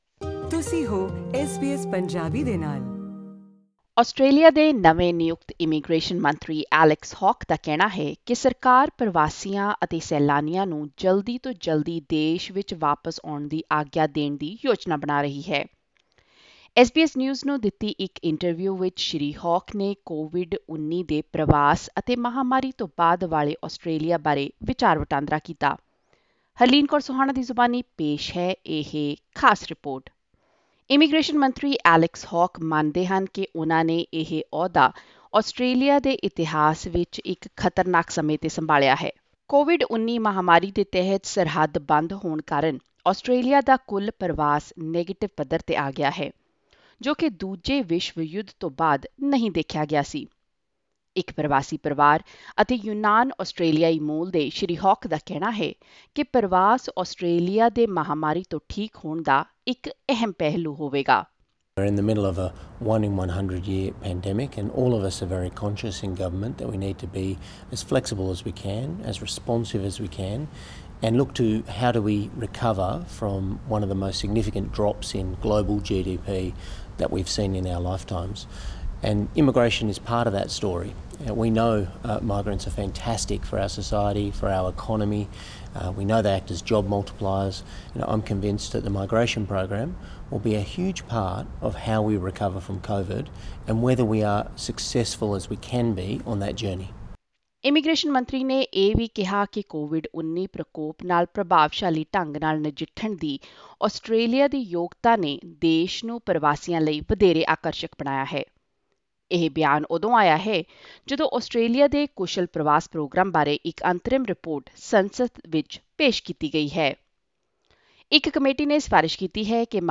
In an interview with SBS News, Mr Hawke discussed the impacts of COVID-19 on migration and a post-pandemic Australia.